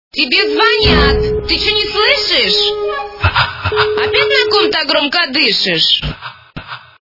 » Звуки » Люди фразы » Тебе звонят! - Ты чё не слышишь?
При прослушивании Тебе звонят! - Ты чё не слышишь? качество понижено и присутствуют гудки.